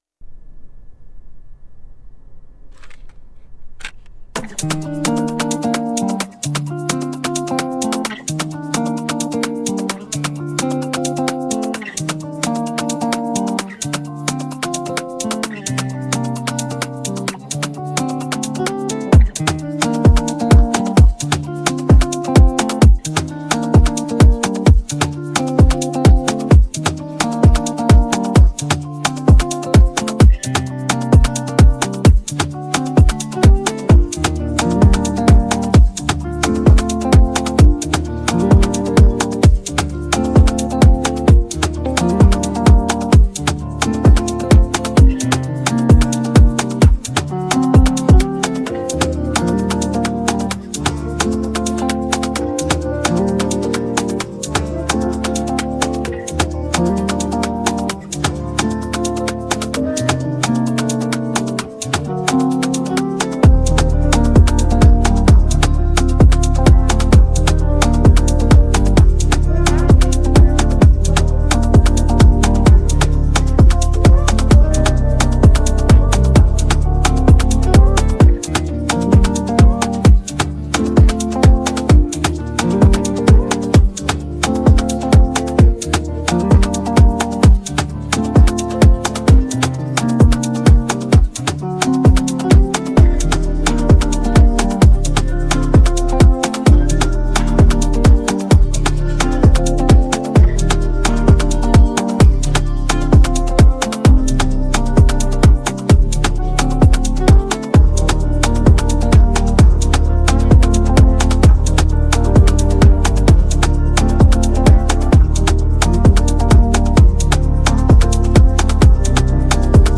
Afro popHip hopPop